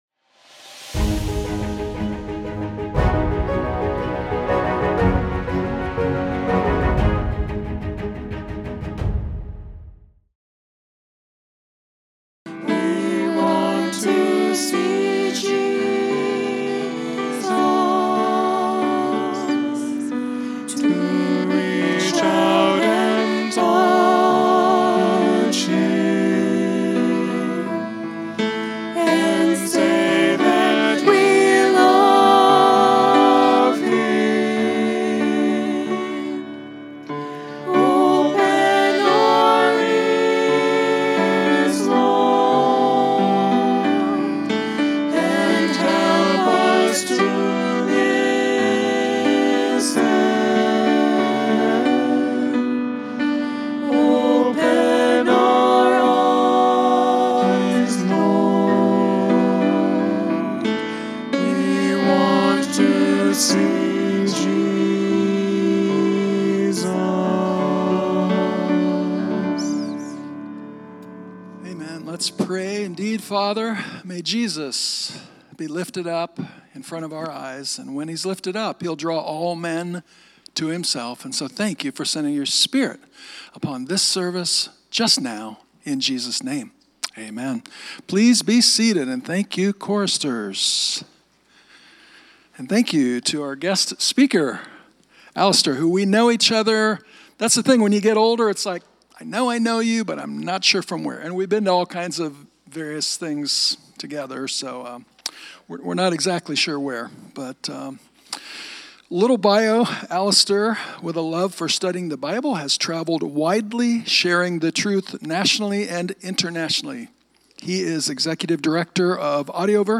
Sermon Archive – Sacramento Central Seventh-day Adventist Church